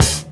OHHKICK.wav